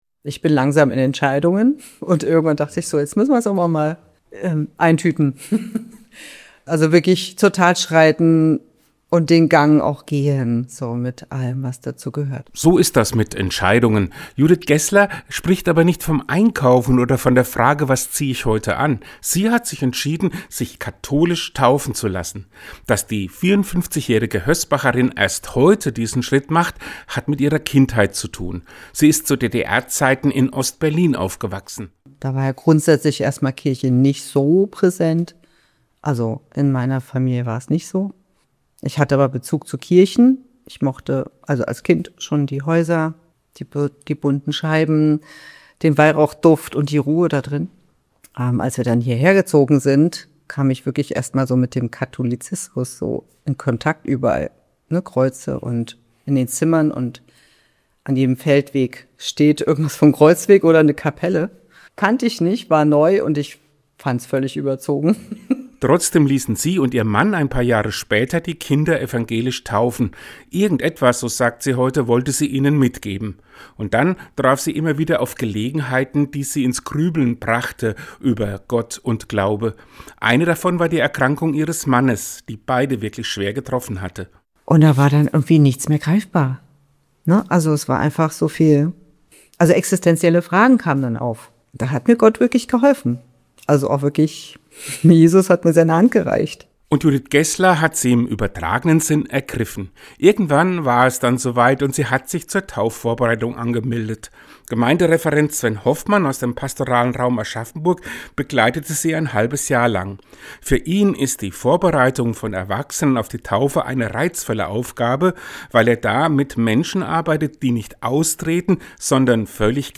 Den Radiopodcast gibt es unten als Download!